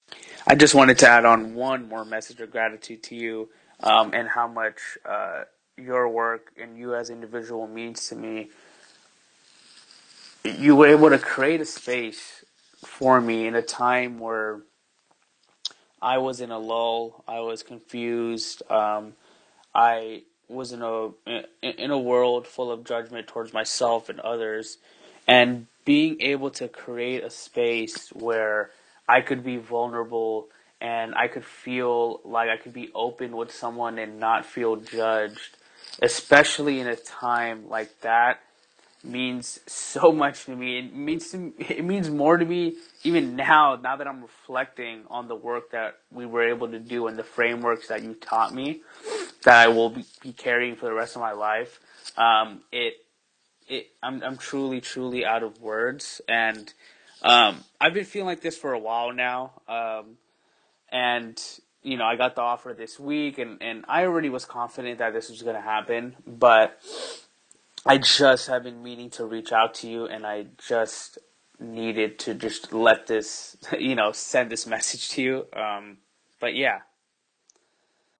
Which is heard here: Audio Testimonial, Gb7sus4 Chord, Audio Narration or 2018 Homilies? Audio Testimonial